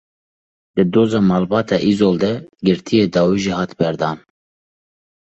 /bɛɾˈdɑːn/